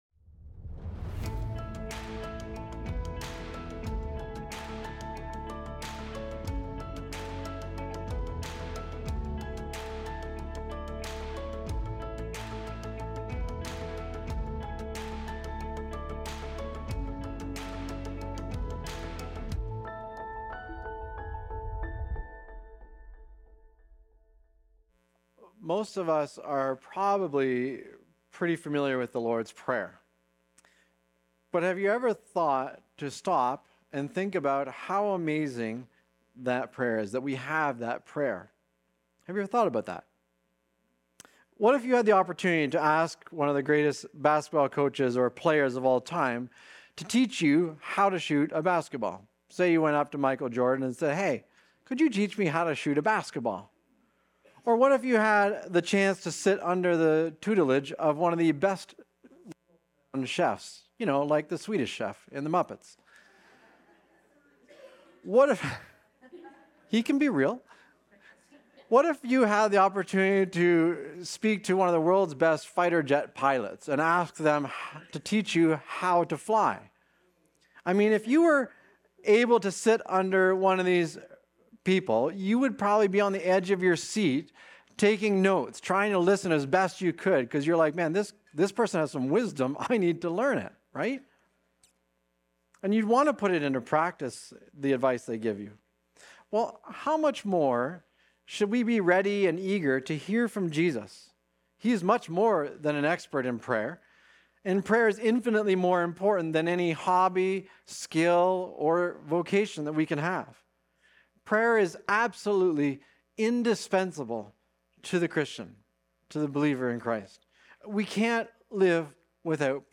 Recorded Sunday, January 18, 2026, at Trentside Fenelon Falls.